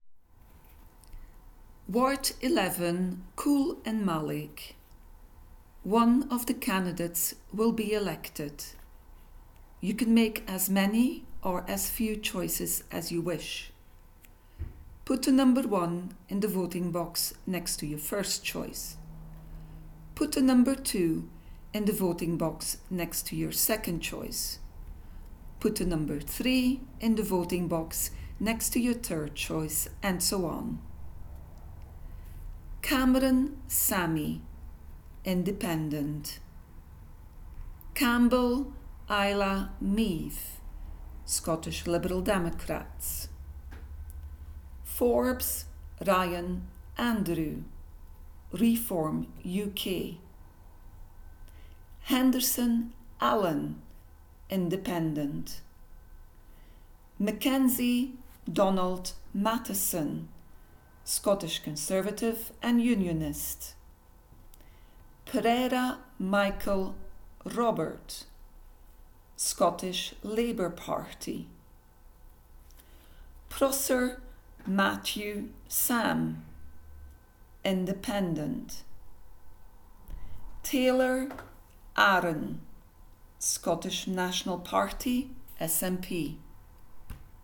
spoken_ballot_for_ward_11_-_caol_and_mallaig.m4a